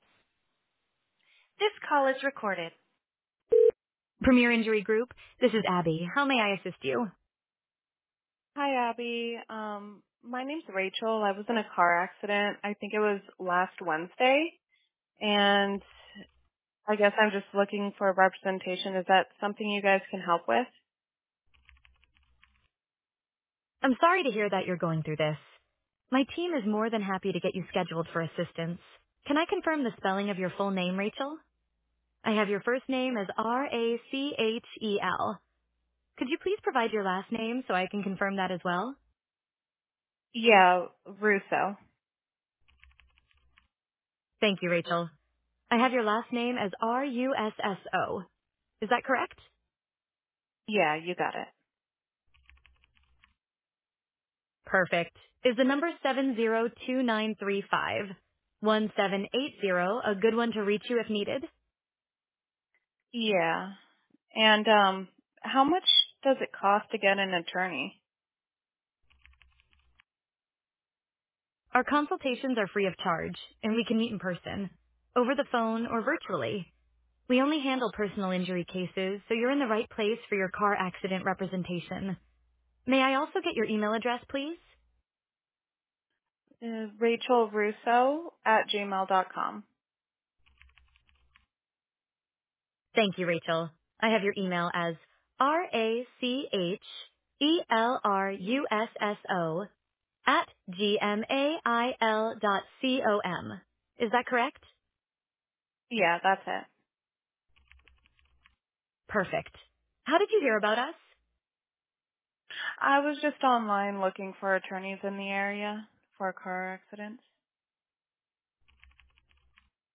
AI Receptionist
AI-Legal-2.mp3